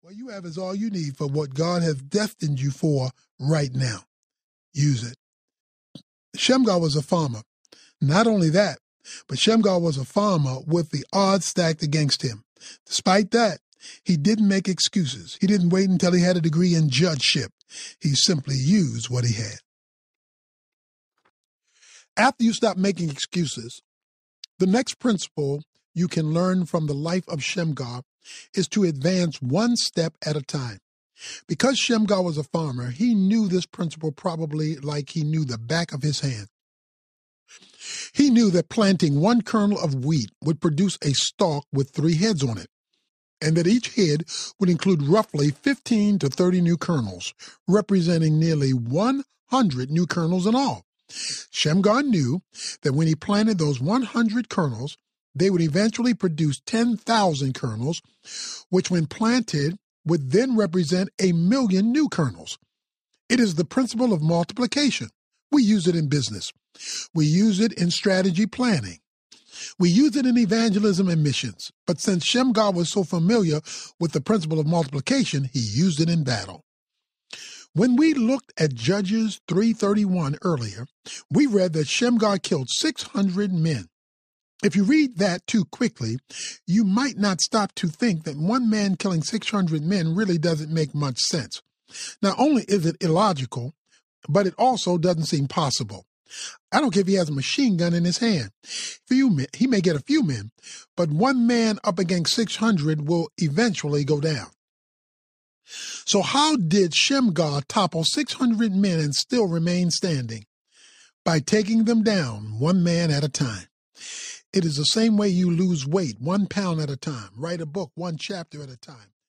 Kingdom Man Audiobook
Narrator